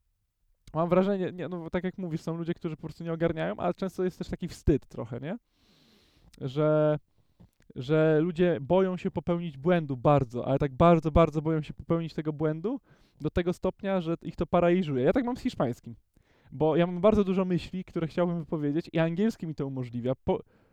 Male: Conversation
Mężczyzna: Rozmowa
ID053_conversation.wav